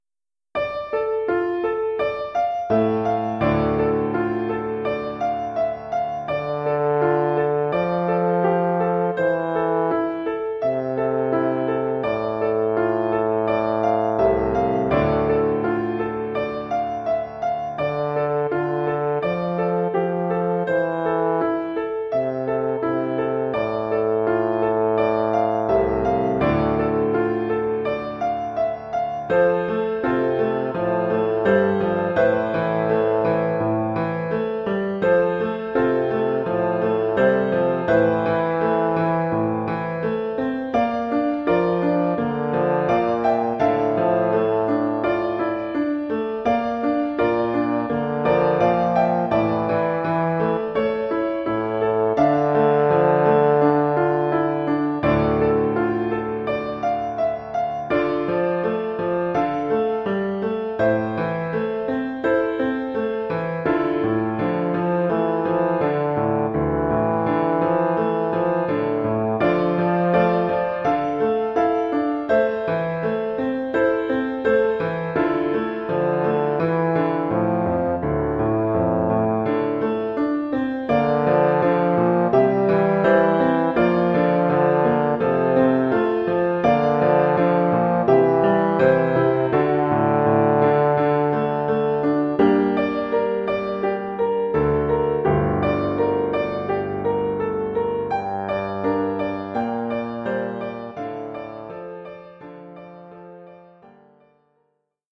Formule instrumentale : Saxhorn basse/Tuba et piano
Oeuvre pour saxhorn basse / euphonium /
tuba et piano.